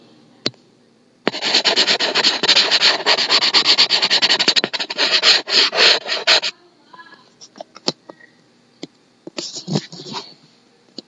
钳形纸
Tag: 大声的